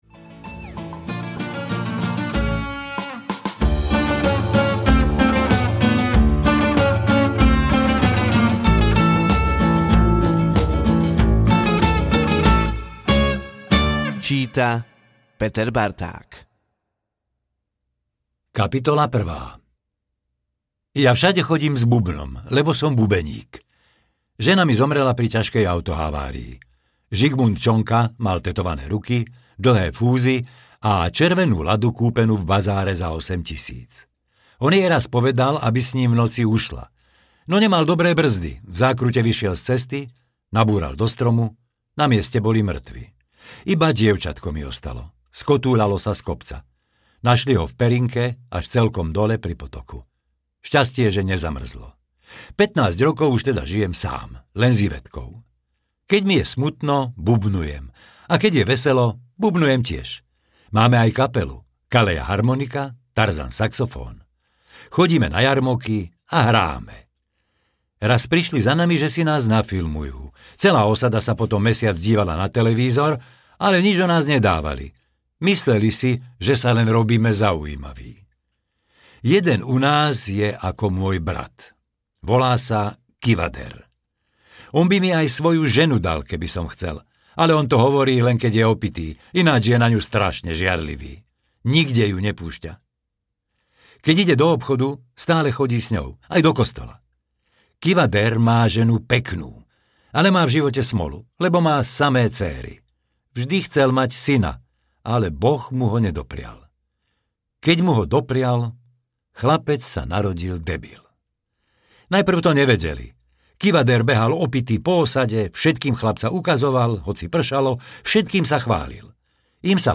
Kivader audiokniha
Ukázka z knihy